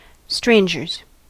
Ääntäminen
Ääntäminen US : IPA : ['streɪn.dʒərz] Haettu sana löytyi näillä lähdekielillä: englanti Strangers on sanan stranger monikko.